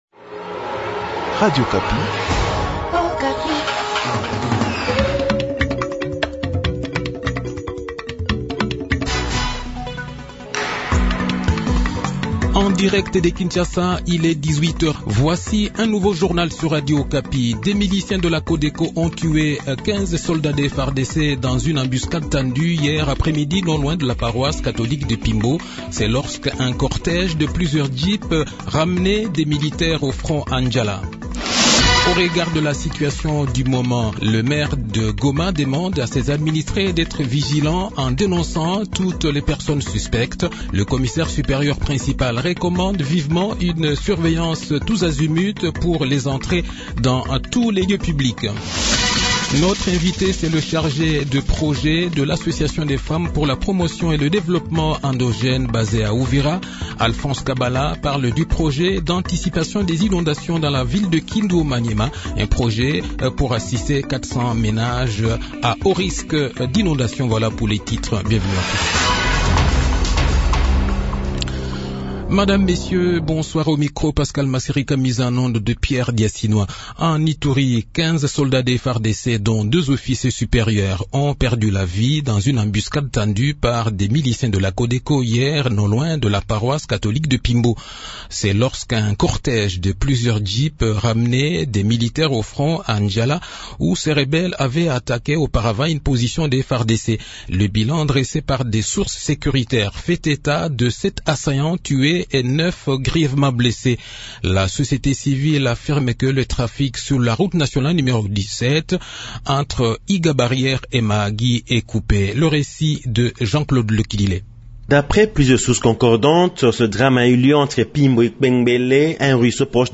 e journal de 18 h, 28 janvier 2023